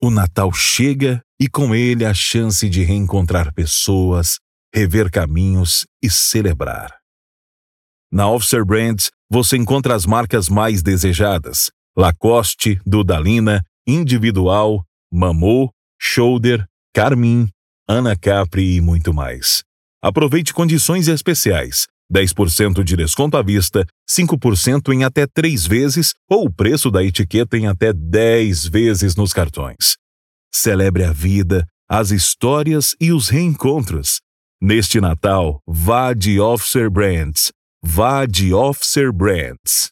Off com 30 segundos